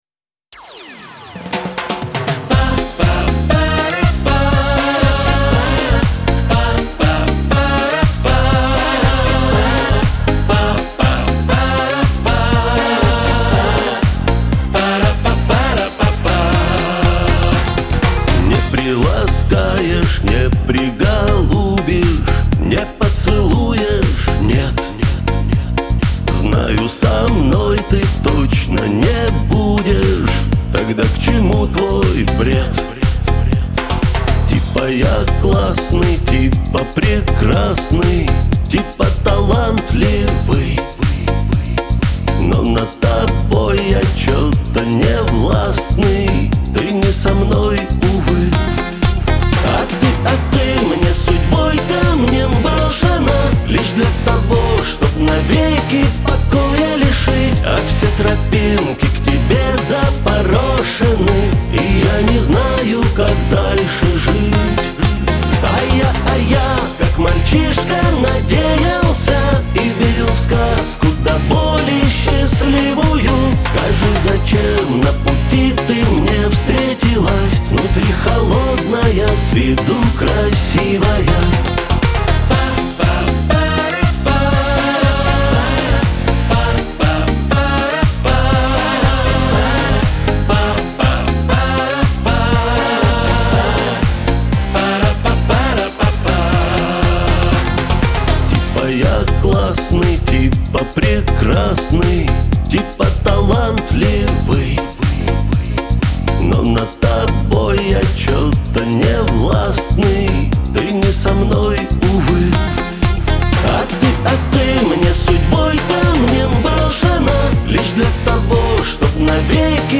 эстрада
диско